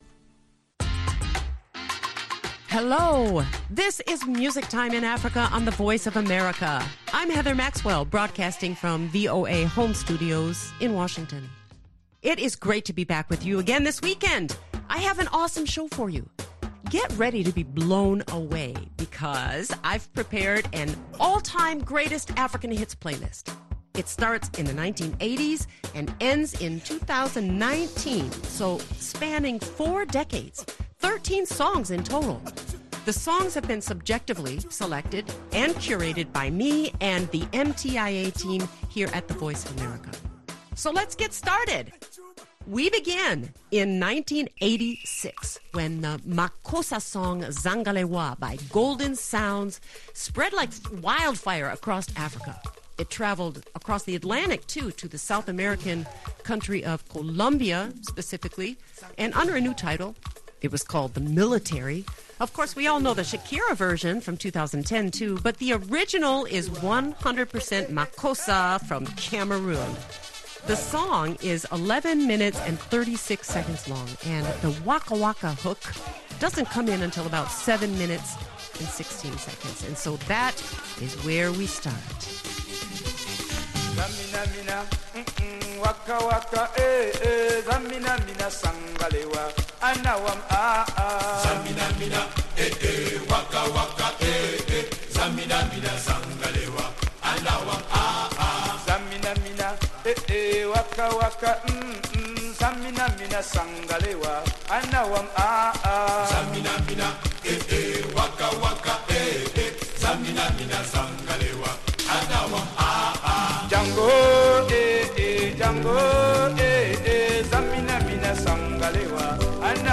world music
zouglou megahit
South African global dance sensation